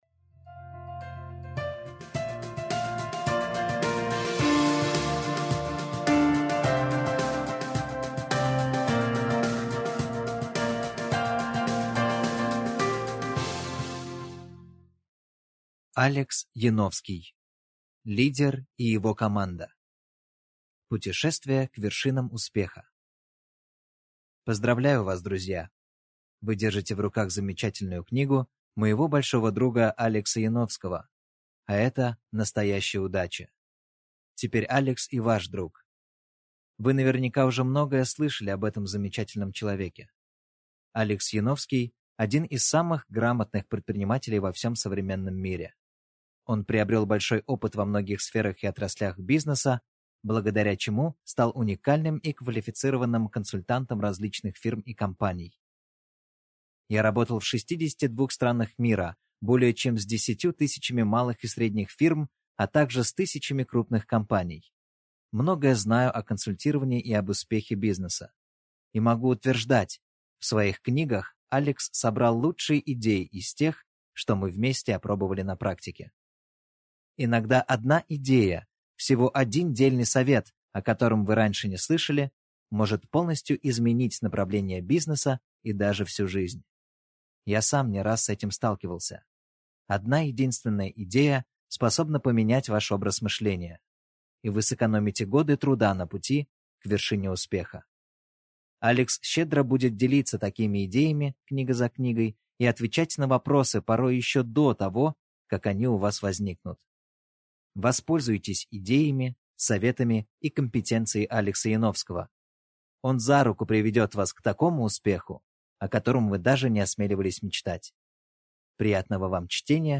Аудиокнига Лидер и его команда | Библиотека аудиокниг
Прослушать и бесплатно скачать фрагмент аудиокниги